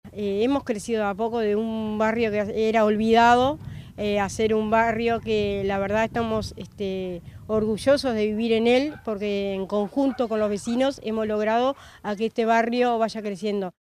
alba_torres_concejala_municipio_nicolich.mp3